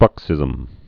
(brŭksĭzəm)